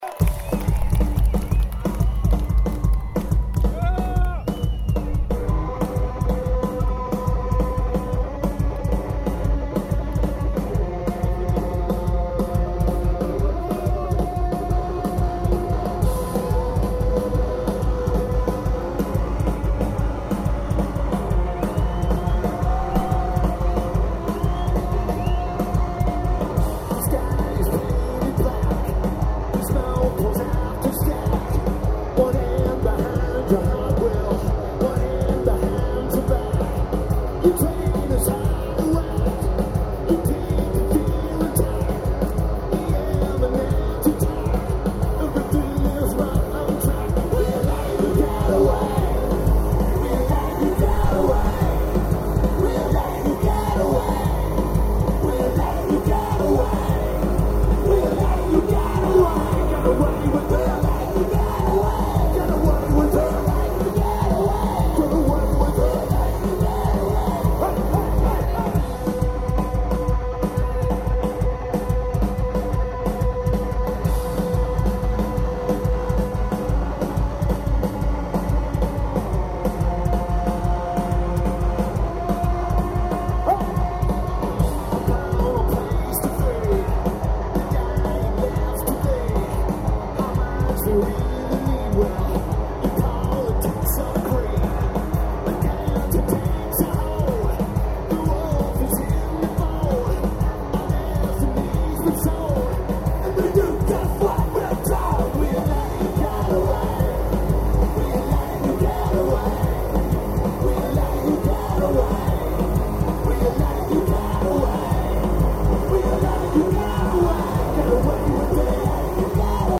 Drums
Guitar